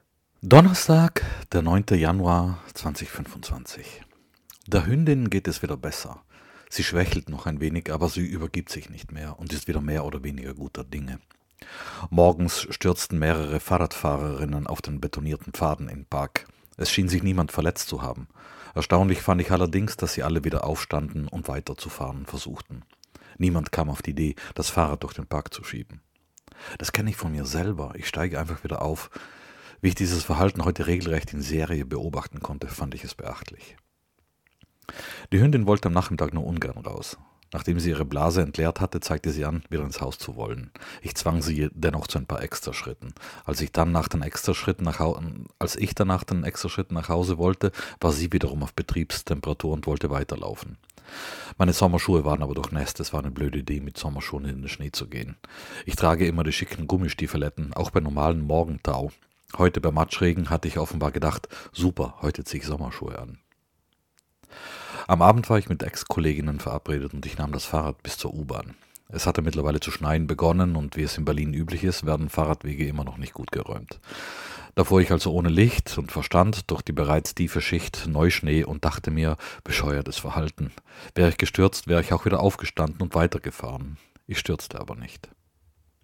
[Do, 9.1.2025 - ohne Licht und Verstand] - es regnet